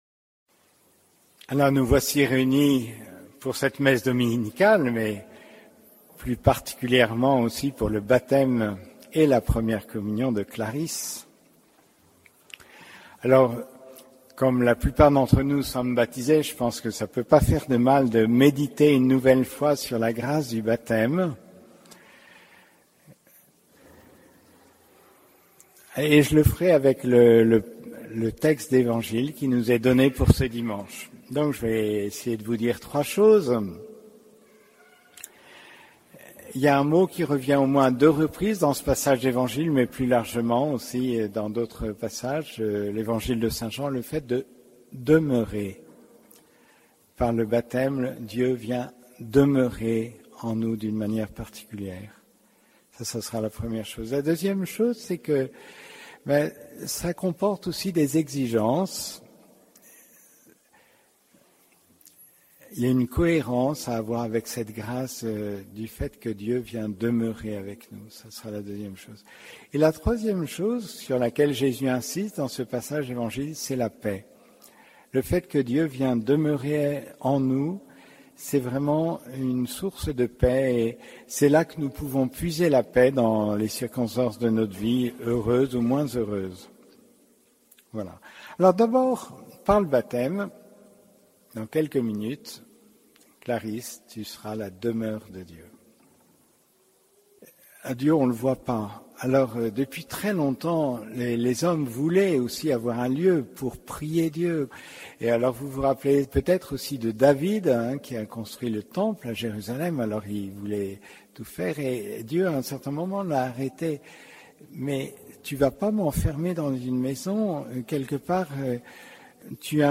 Homélie du sixième dimanche de Pâques